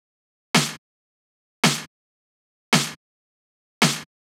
03 Snare.wav